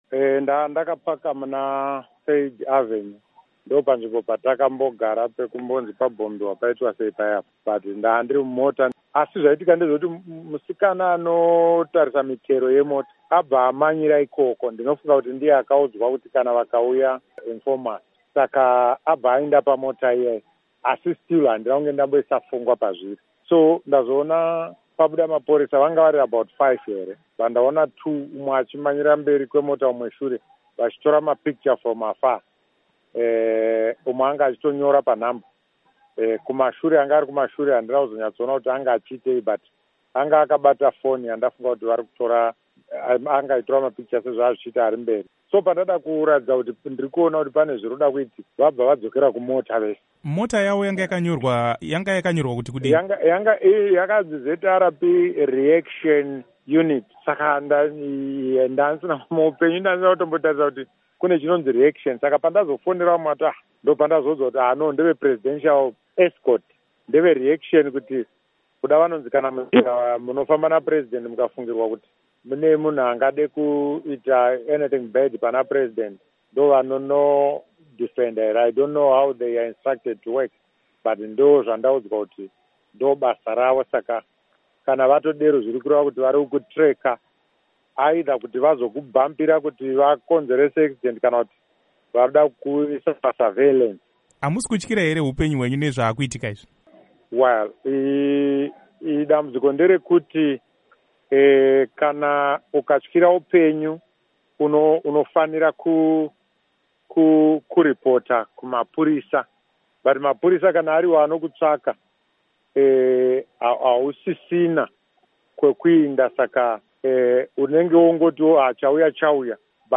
Hurukuro naVaVictor Matemadanda